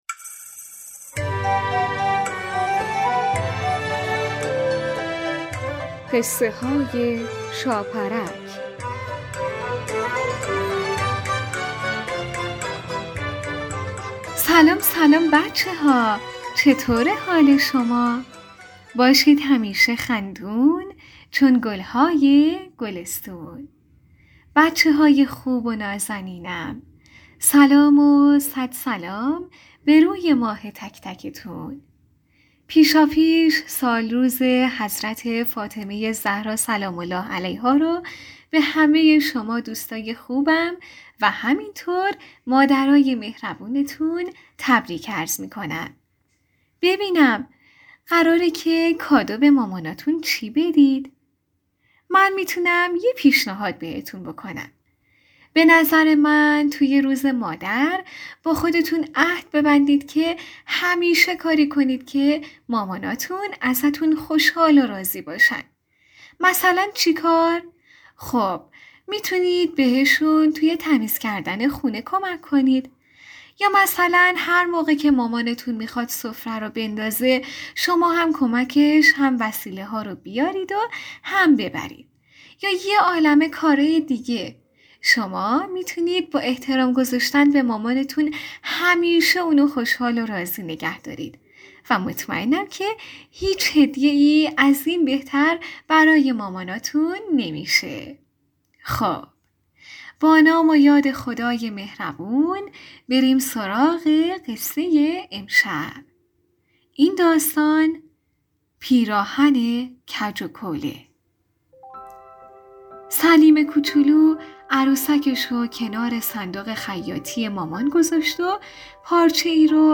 این ویژه برنامه با هدف نهادینه شدن فرهنگ نماز در بین کودکان با بیان قصه های شیرین نمازی تولید و منتشر می شود.